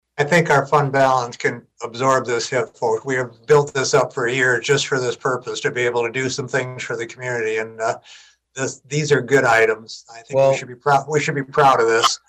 Mayor Tom Kramer says the projects can become a reality thanks to the city’s fund balance.